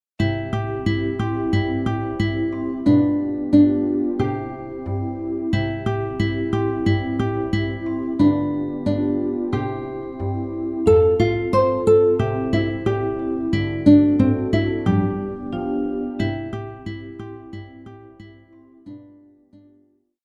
RÉPERTOIRE  ENFANTS